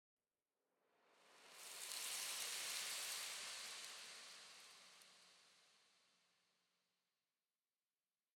Minecraft Version Minecraft Version snapshot Latest Release | Latest Snapshot snapshot / assets / minecraft / sounds / ambient / nether / soulsand_valley / sand1.ogg Compare With Compare With Latest Release | Latest Snapshot
sand1.ogg